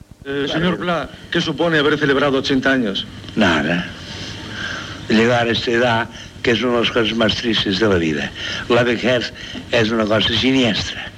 Paraules de l'escriptor Josep Pla en complir 80 anys.
Extret de Crònica Sentimental de Ràdio Barcelona emesa el dia 5 de novembre de 1994.